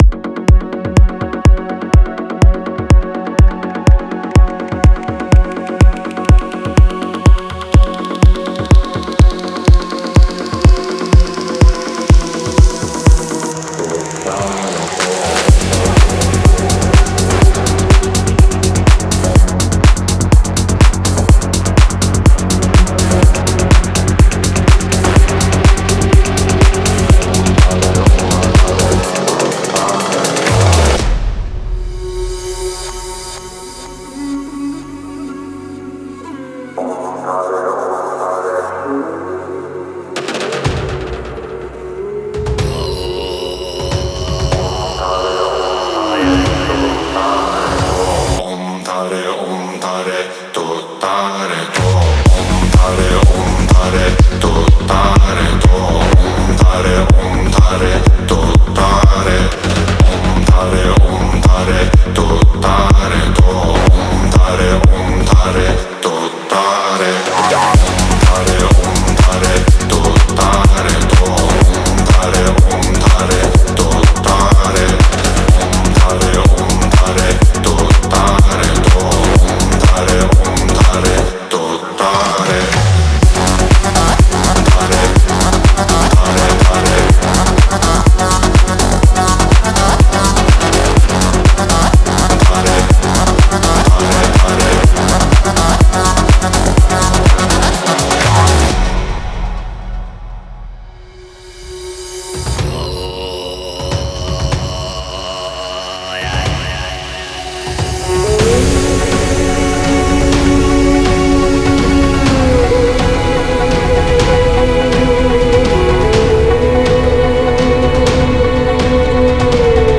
• Жанр: Electronic, Dance